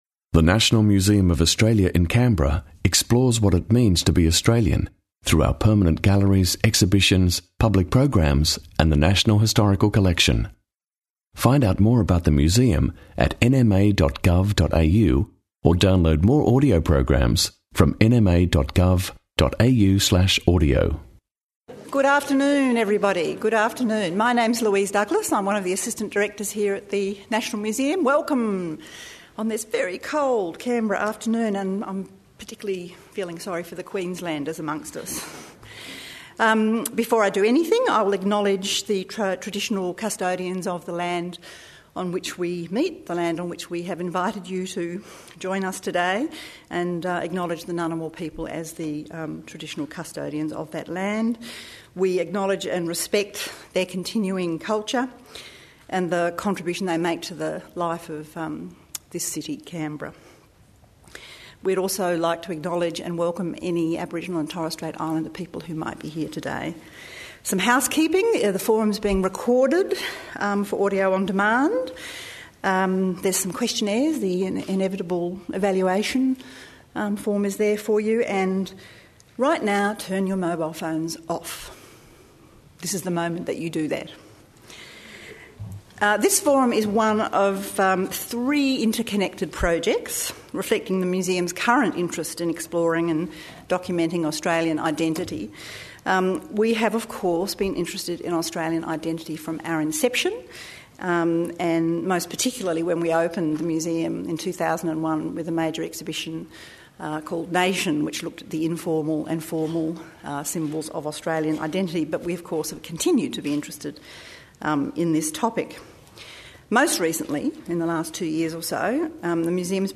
Symbols of Australia public forum with Rod Quantock | National Museum of Australia